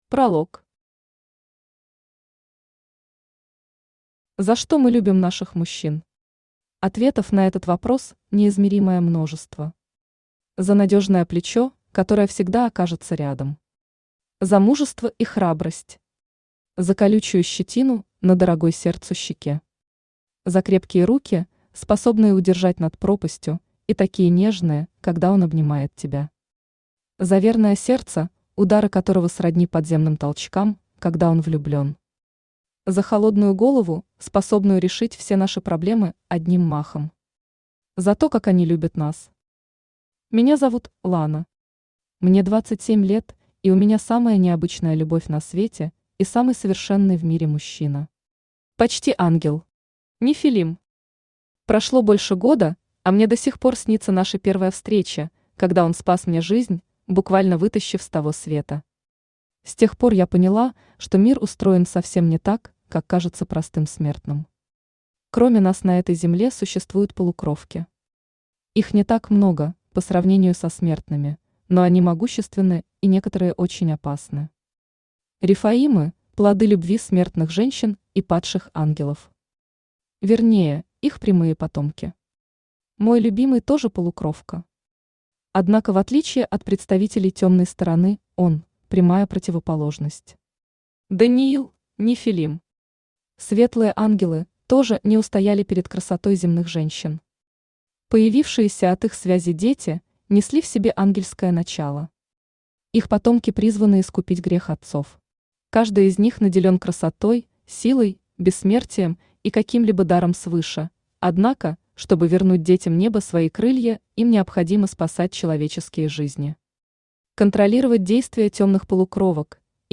Аудиокнига Сага «Когда вырастают крылья». Книга II. Небо в сердце | Библиотека аудиокниг
Небо в сердце Автор Лариса Черногорец Читает аудиокнигу Авточтец ЛитРес.